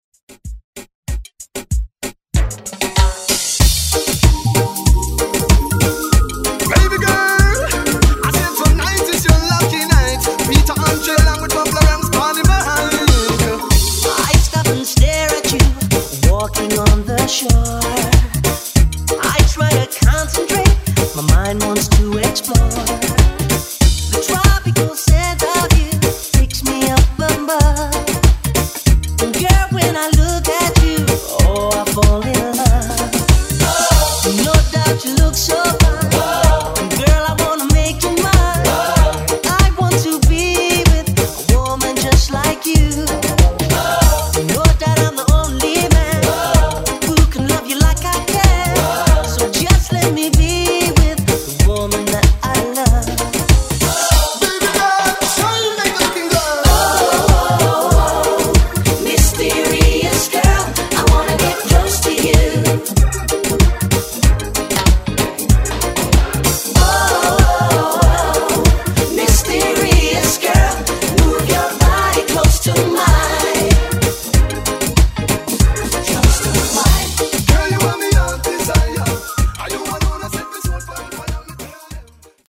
Genre: DANCE
Clean BPM: 126 Time